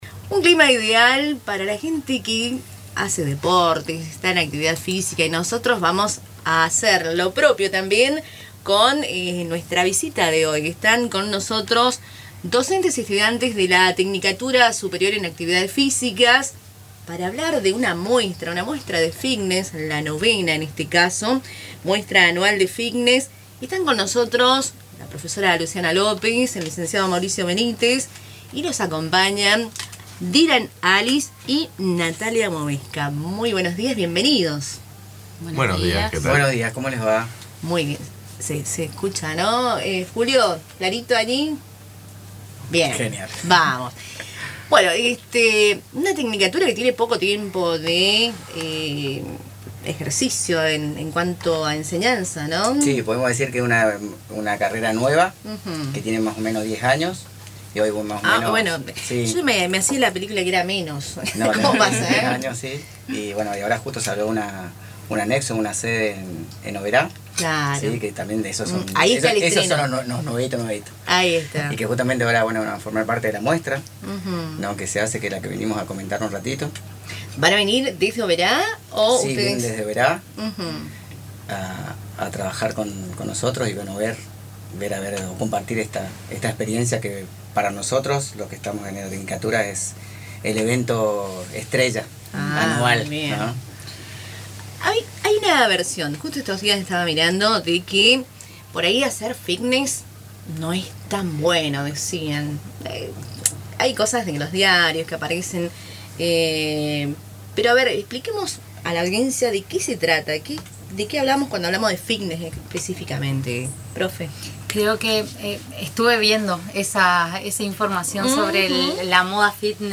En los estudios de Radio Tupambaé, docentes y estudiantes de la Tecnicatura Superior en Actividades Físicas con orientación en Musculación y Entrenamiento Personalizado del Instituto Superior Antonio Ruiz de Montoya (ISARM) presentaron detalles de la IX Muestra Anual de Fitness, que se realizará el martes 7 de octubre, a las 19, en el auditorio del instituto. La entrada será libre y gratuita, aunque se solicita la colaboración de un alimento no perecedero.